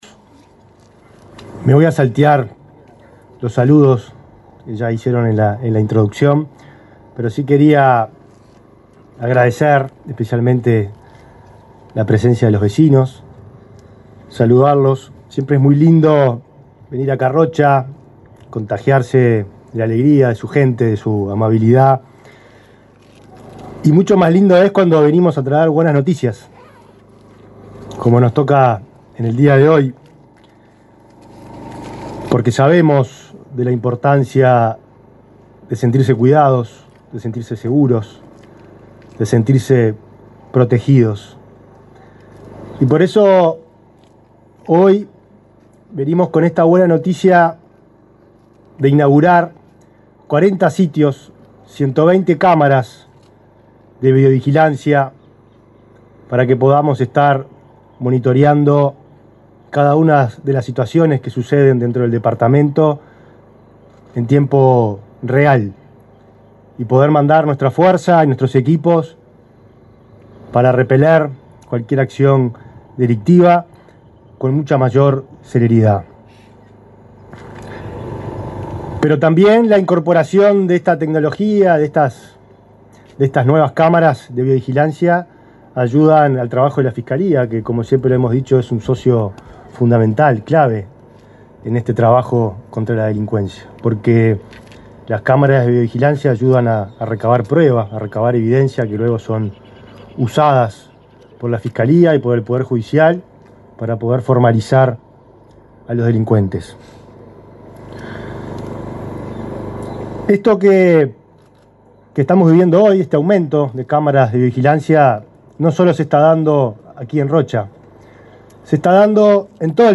Palabras del ministro del Interior, Nicolás Martinelli
El ministro del Interior, Nicolás Martinelli, participó, este viernes 3 en Rocha, presentó 95 cámaras de videovigilancia en la ciudad capital.